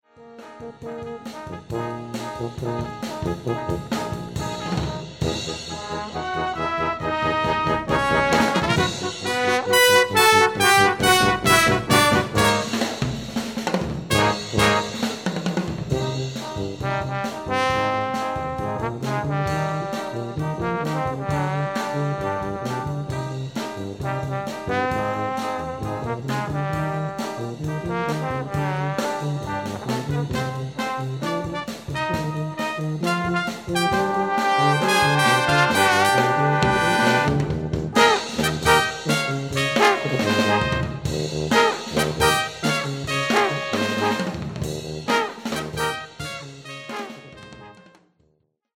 Beach Band tunes - mp3's:
6-piece pand